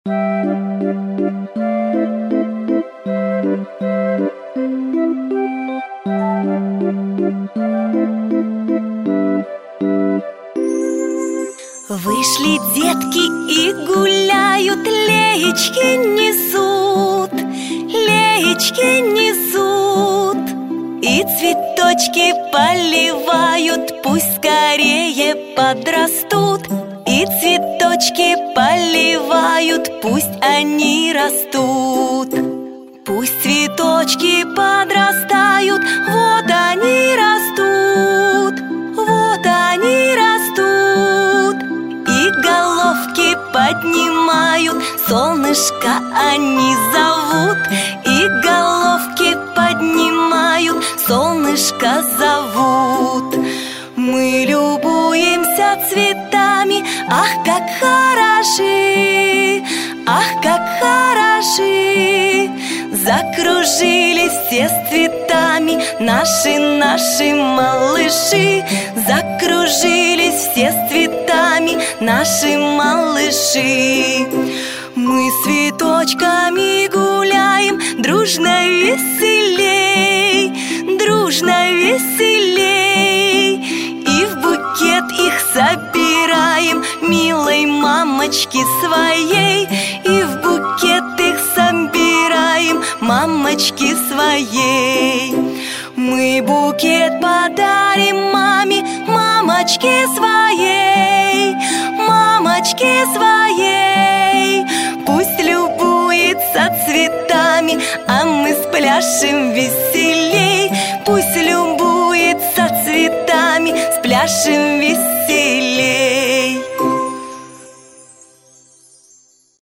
веселая танцевальная песня про цветы для малышей в садике